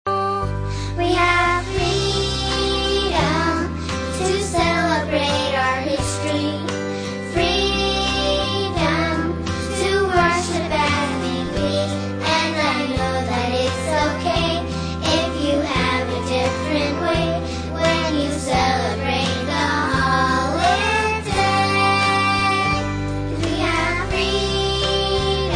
A Winter Holidays Concert Song
Instrumental and vocal tracks